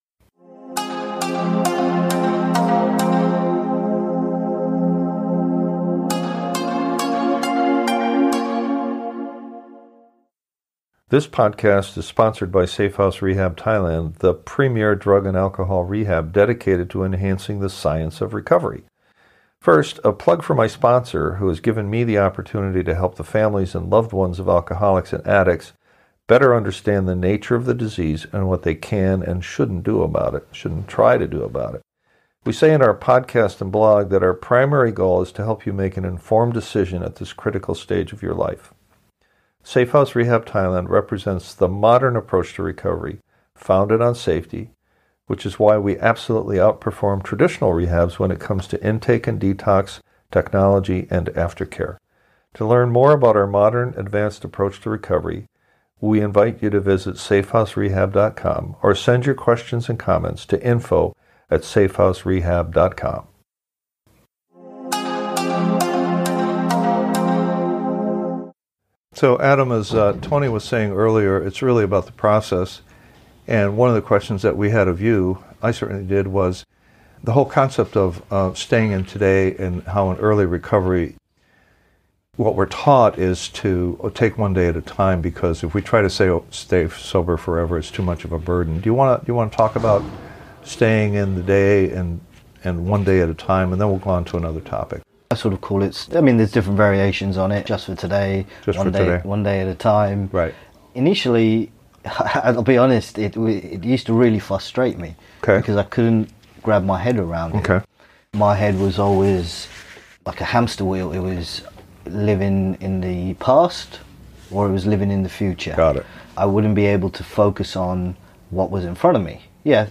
A dialogue